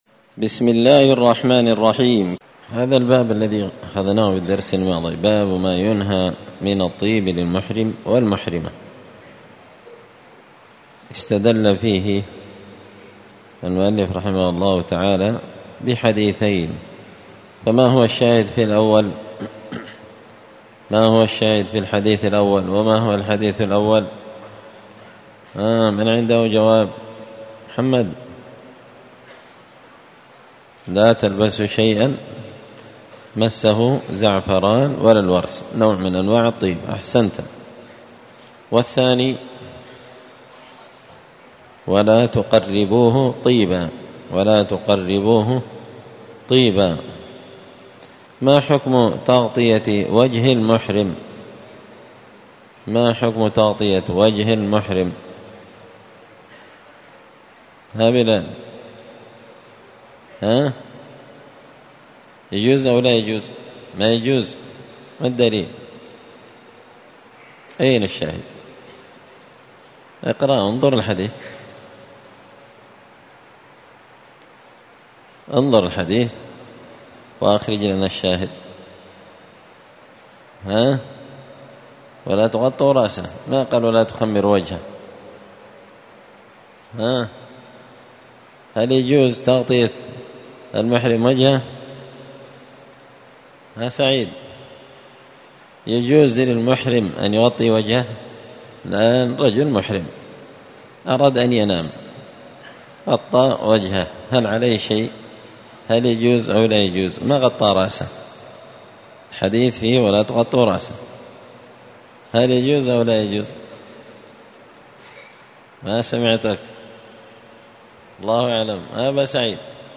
كتاب جزاء الصيد من شرح صحيح البخاري- الدرس 14 بَابٌ الِاغْتِسَالِ لِلْمُحْرِمِ .